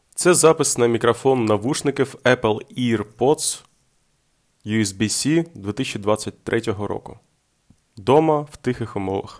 Мікрофон чудовий 10 з 10